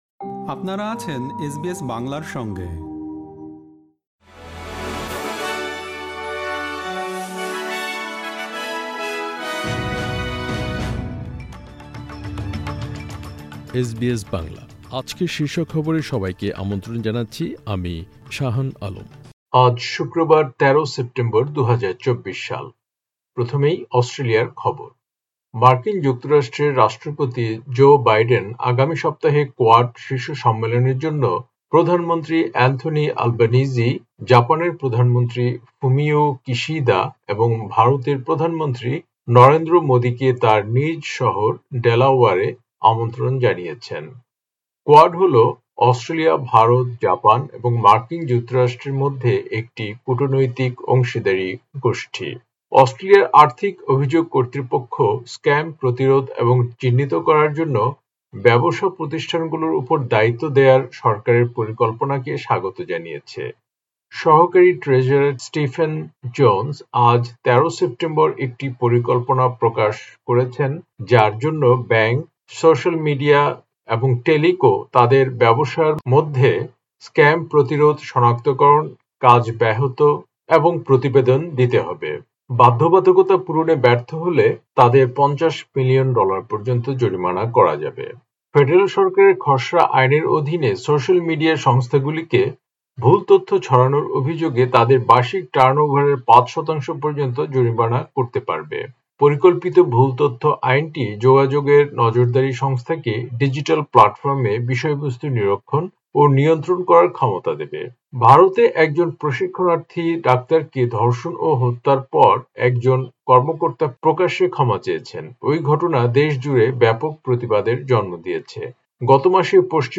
এসবিএস বাংলা শীর্ষ খবর: ১৩ সেপ্টেম্বর, ২০২৪